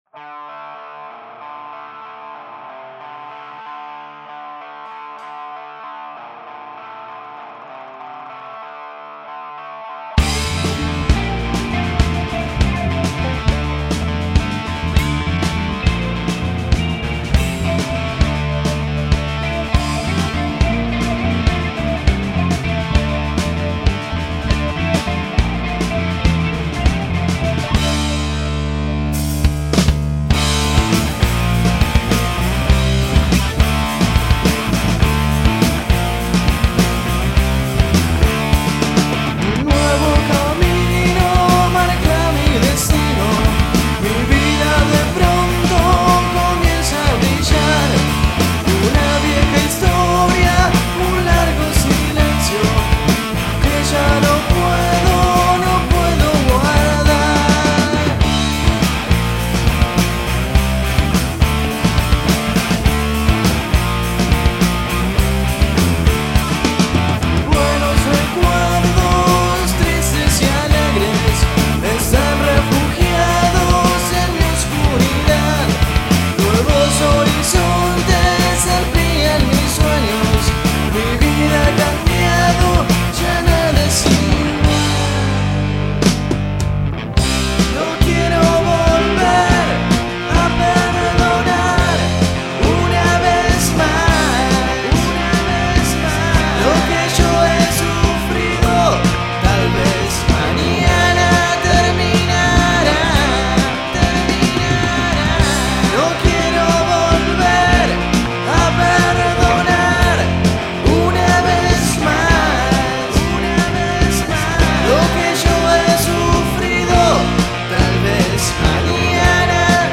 Rock ciudadevitence!!!!!
guitarra y Voz
Bateria
Bajo
Gitarra